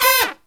FALL HIT02-L.wav